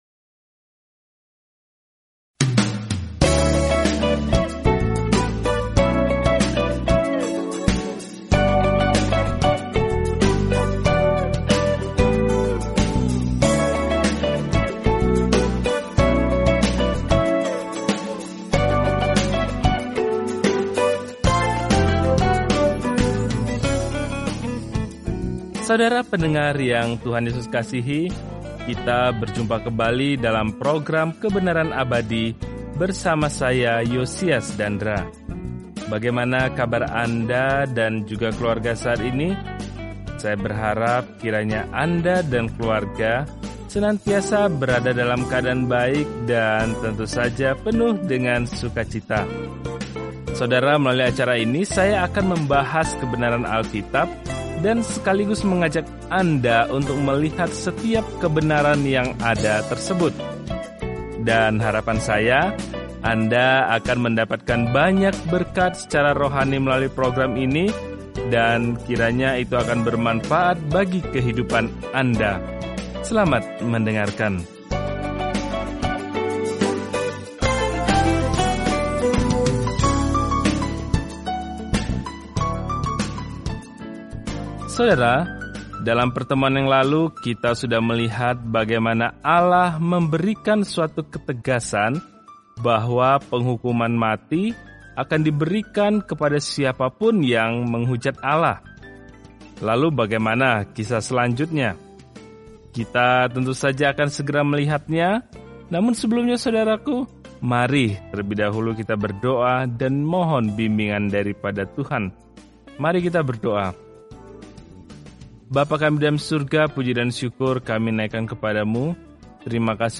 Dalam ibadah, pengorbanan, dan rasa hormat, Imamat menjawab pertanyaan itu bagi Israel zaman dahulu. Jelajahi Imamat setiap hari sambil mendengarkan studi audio dan membaca ayat-ayat tertentu dari firman Tuhan.